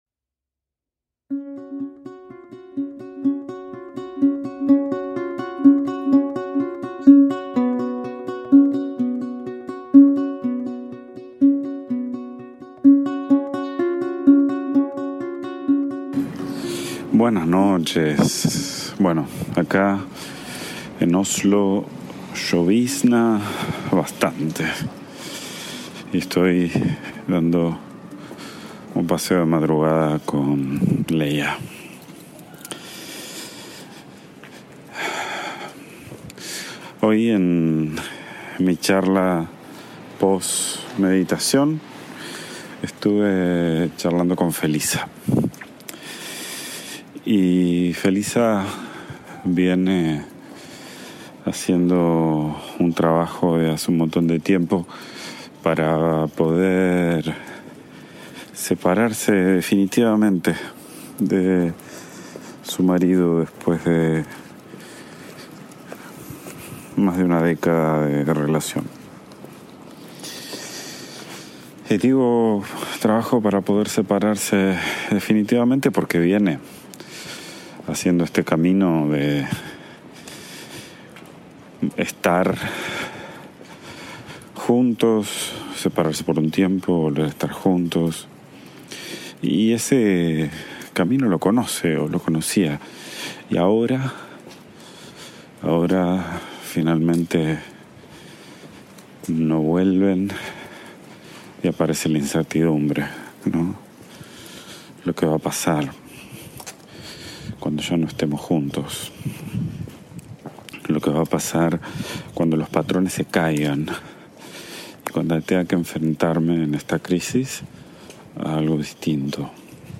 IMPORTANTE: Esta serie fue grabada durante una época de muchos viajes, directamente en mi teléfono móvil. La calidad del audio no está a la altura de lo que escucharás en las siguientes series y episodios.